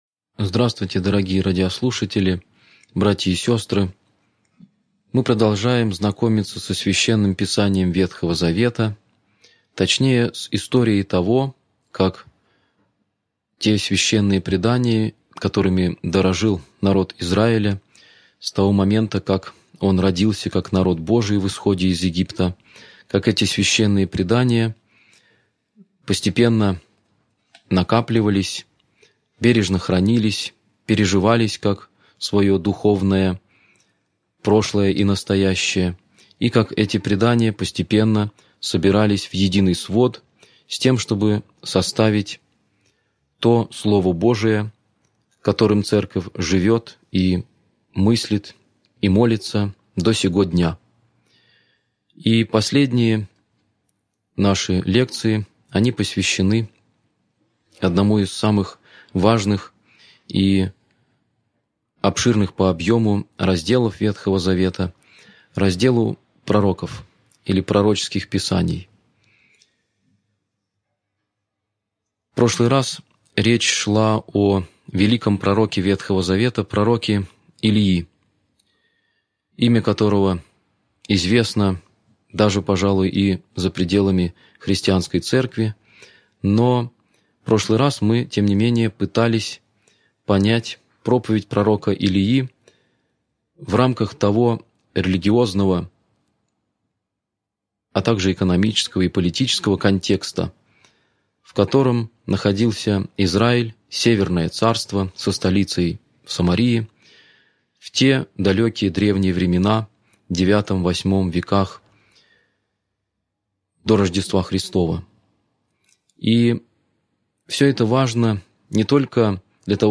Аудиокнига Лекция 9. Пророк Амос | Библиотека аудиокниг